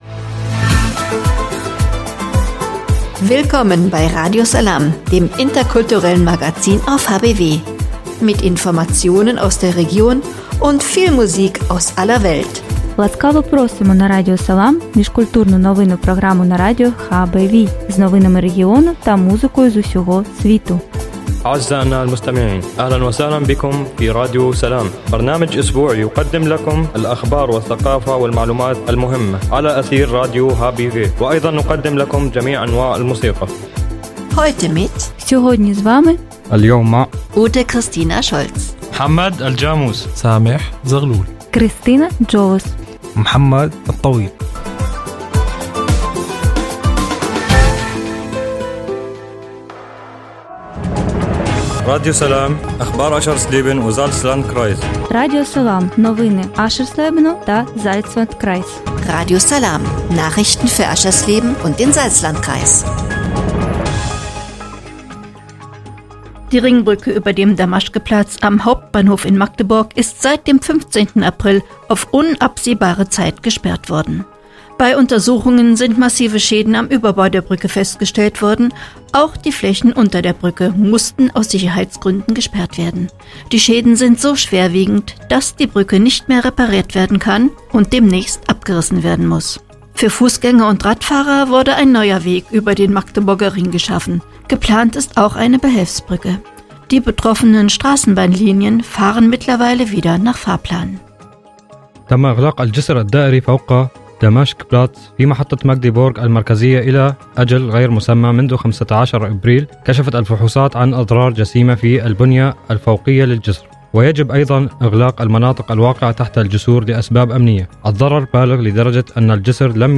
„Radio Salām“ heißt das interkulturelle Magazin auf radio hbw. Mit dem Wochenmagazin wollen die Macher alte und neue Nachbarn erreichen: diejenigen, die schon lange in Harz und Börde zu Hause sind, und ebenso Geflüchtete, beispielsweise aus Syrien.